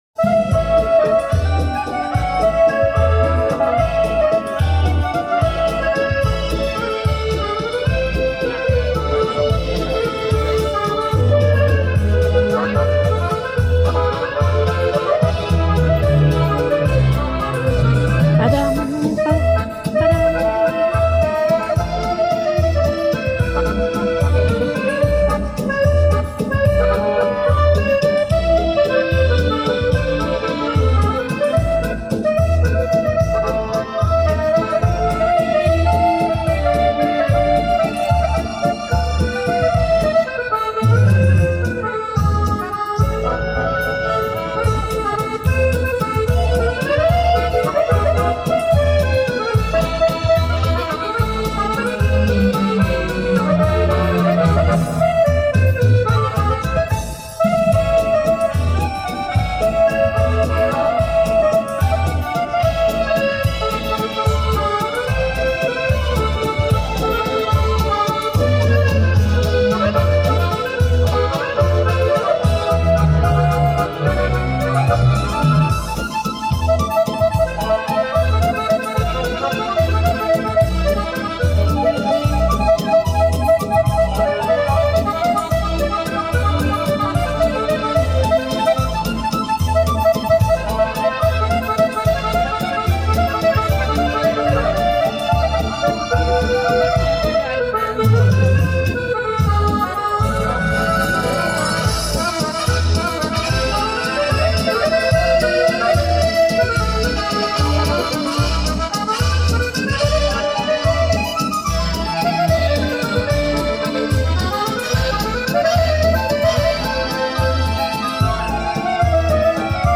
Кисловодск. Курортный бульвар. Уличные музыканты, как воздух апреля 2013.